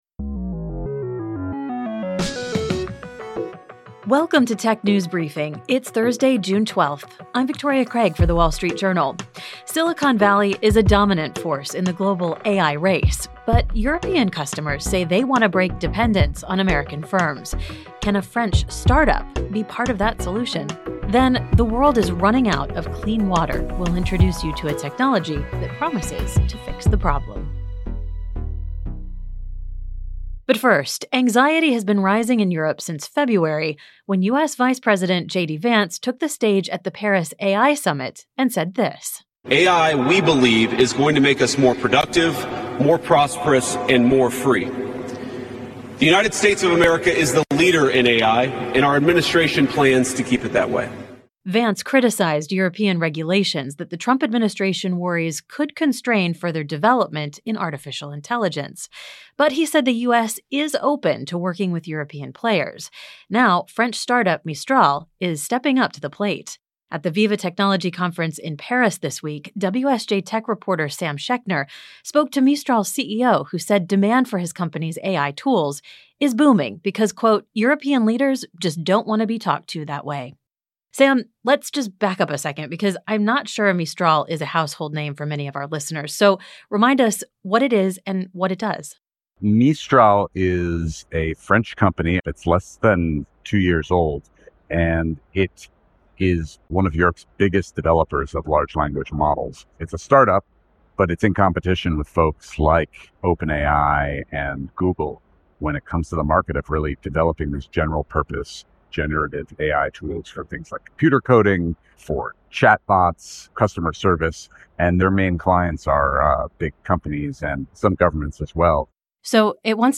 reports from the Viva Technology conference in Paris. Plus, the United Nations estimates half of all people on Earth experience severe water scarcity at least one month of the year.